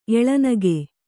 ♪ eḷanage